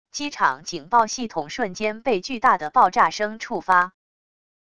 机场警报系统瞬间被巨大的爆炸声触发wav音频